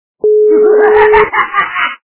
При прослушивании Смех - Хамский качество понижено и присутствуют гудки.
Звук Смех - Хамский